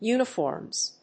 /ˈjunʌˌfɔrmz(米国英語), ˈju:nʌˌfɔ:rmz(英国英語)/